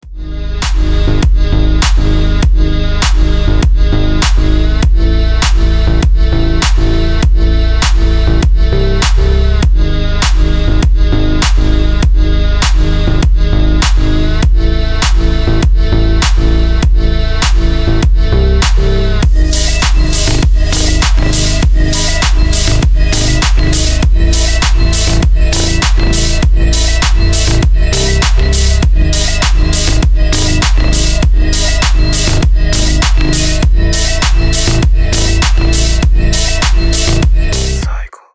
Танцевальные
клубные # громкие # без слов